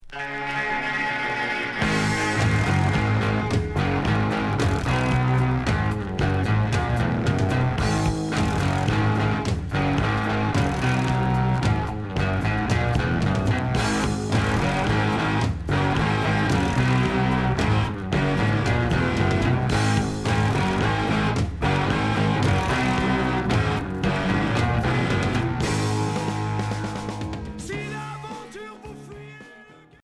Heavy rock pop Onzième 45t retour à l'accueil